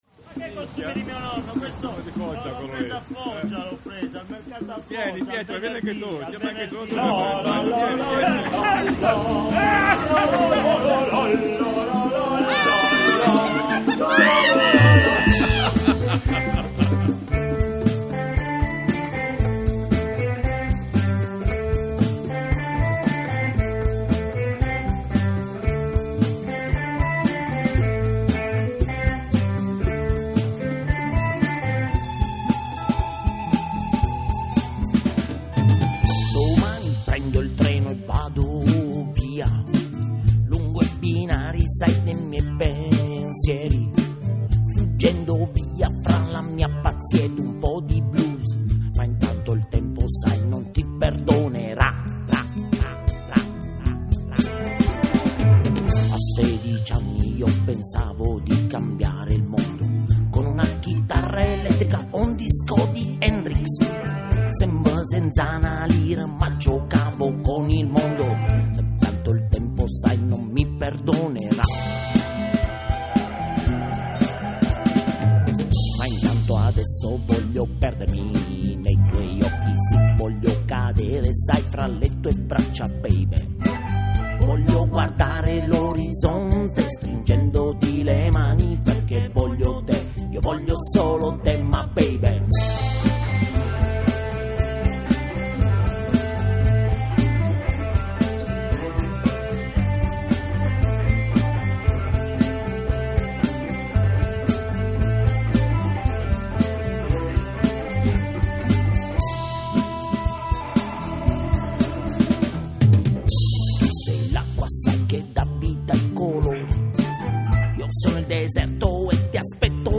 spazia dal blues al reggae pop/rock